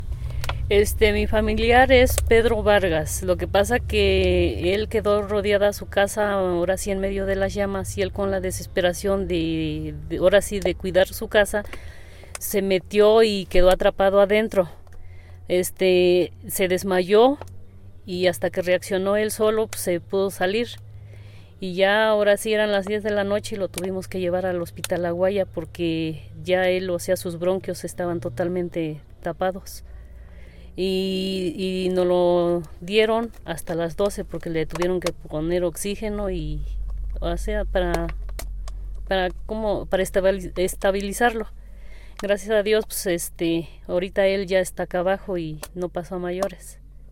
nos platica en entrevista